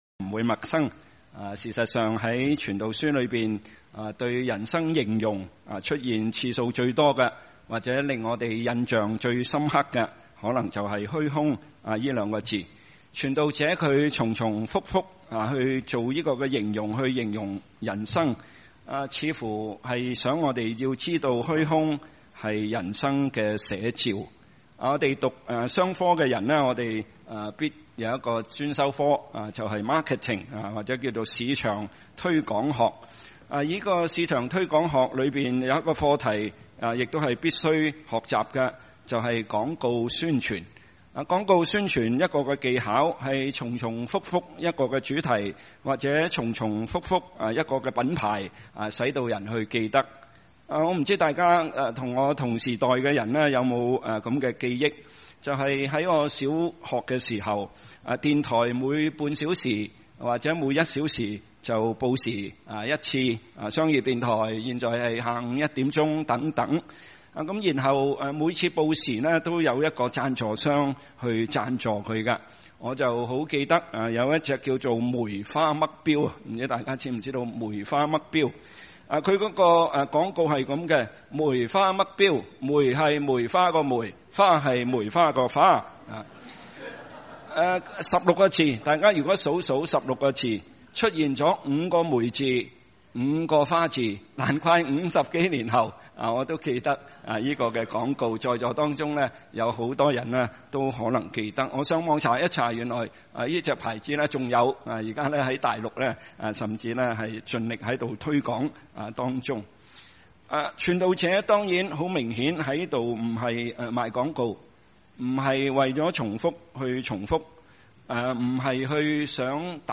華埠粵語二堂 - 頁14，共31 | 波士頓華人佈道會